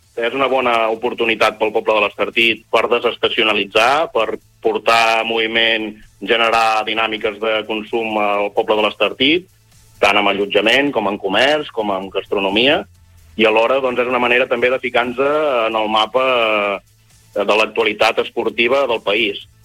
Avui al Supermatí, Francesc Ferrer, president de l’EMD, ha explicat que hi veu moltes avantatges.